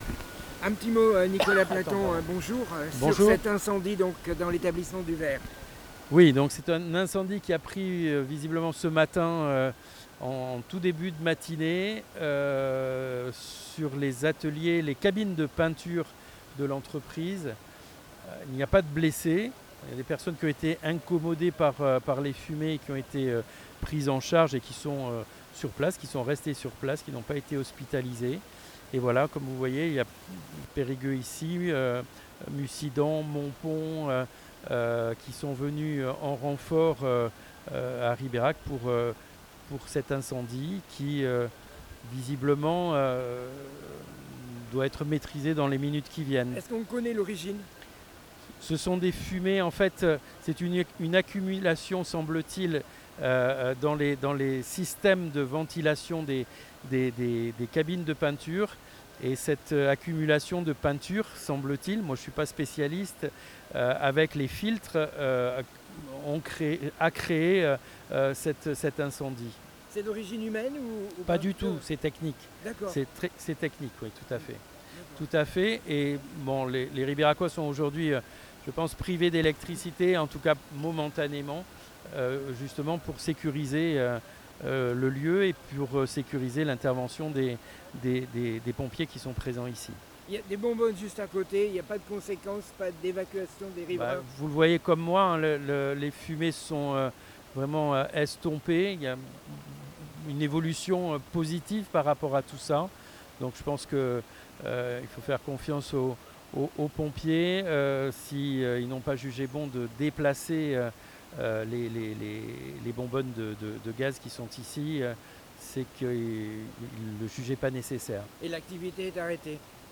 Le maire de RIBERAC apporte des informations concernant l'incendie au sein de l'entreprise DUVERGT a Ribérac